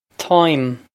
Táim Taw-im
This is an approximate phonetic pronunciation of the phrase.